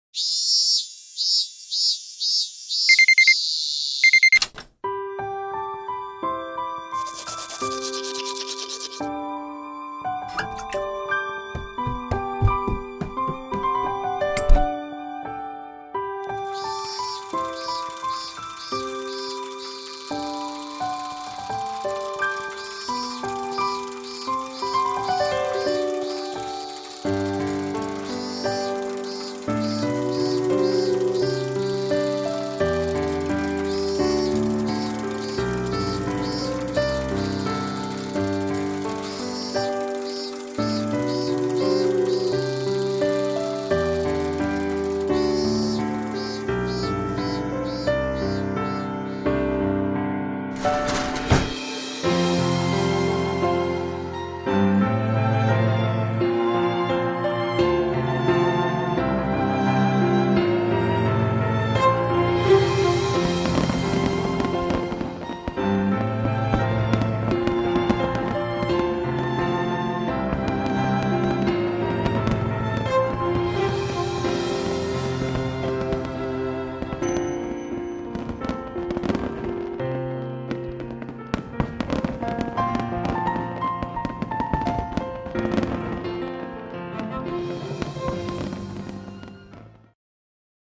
【声劇】夏に鳴いたのは、